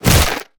Sfx_creature_rockpuncher_flinchsmall_01.ogg